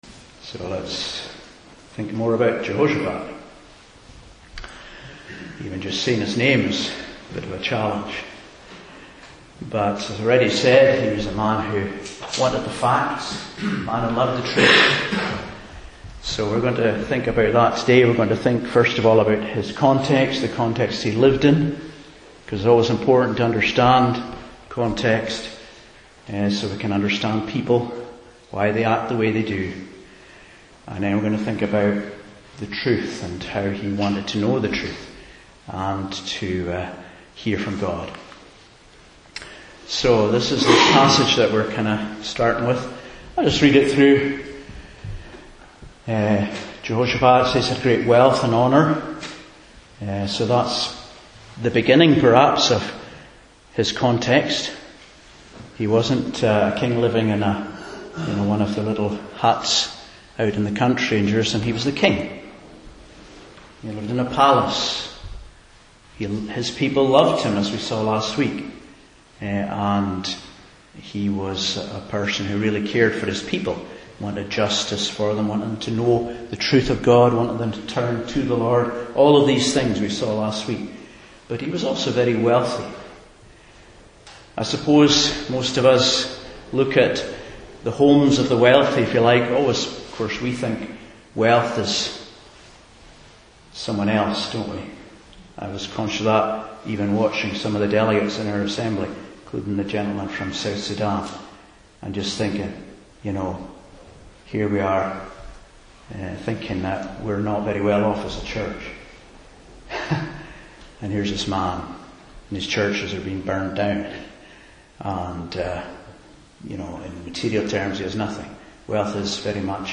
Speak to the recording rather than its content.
2 Chronicles 18:1-27 Service Type: Morning Service 400 said one thing